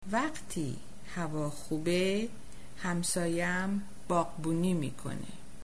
Listen to or Download Sentence 1 in Spoken (Colloquial) style Persian.